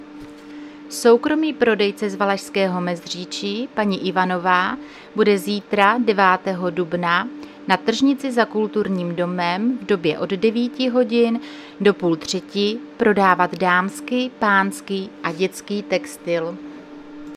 Zařazení: Rozhlas